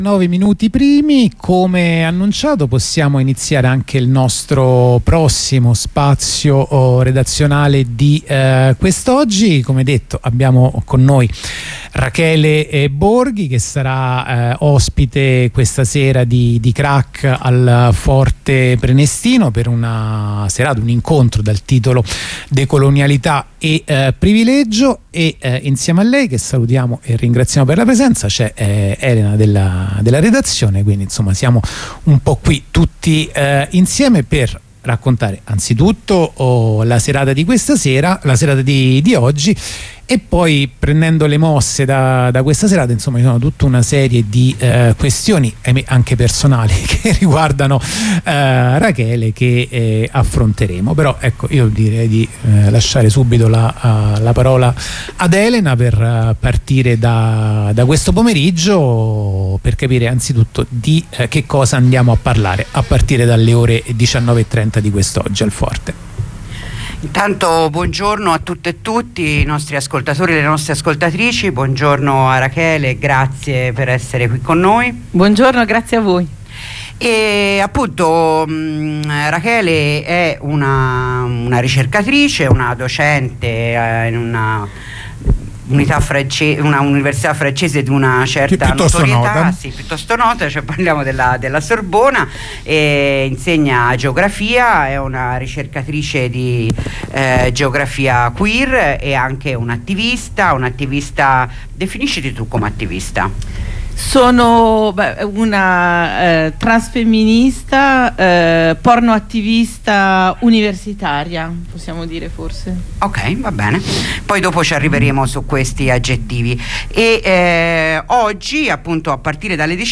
Lettura del comunicato